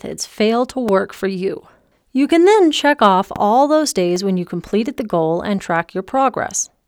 skips and clicks in recording
I have started recording audio books recently so I am very new to a lot of this.
Yes it is in the recording, it is in the same place each time I listen to it and I can see it in the wave form.
I am using a Blue Yeti Microphone and the USB cord is plugged directly into the computer.